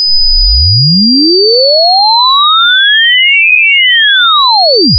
Double frequency sweep, 0 to 5 kHz, 5 seconds.
double-sweep.48k.wav